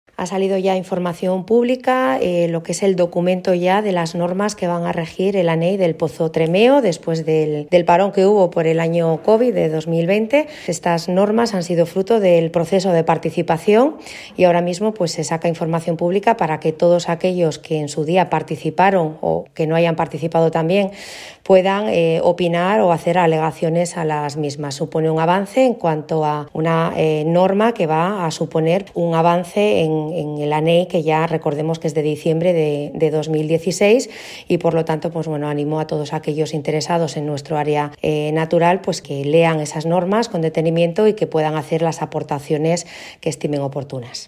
Audio de la alcaldesa Rosa Díaz Fernández
Alcaldesa-sobre-Normas-Pozo-Tremeo.mp3